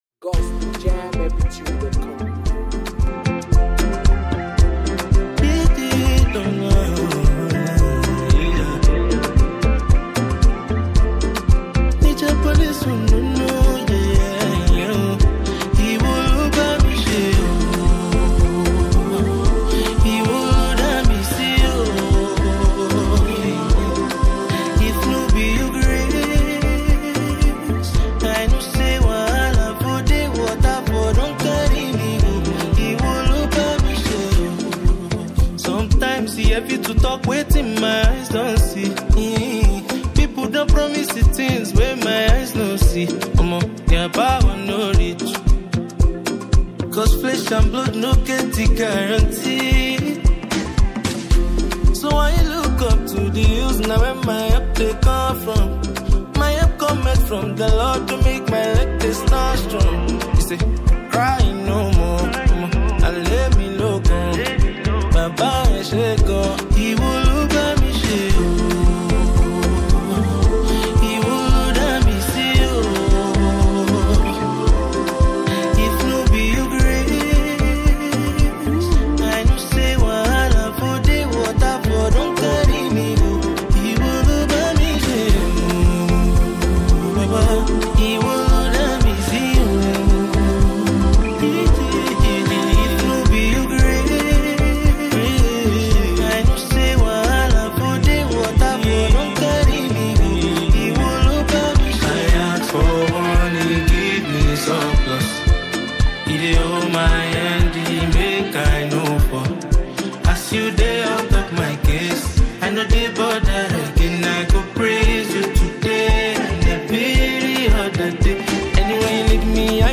Afro beatmusic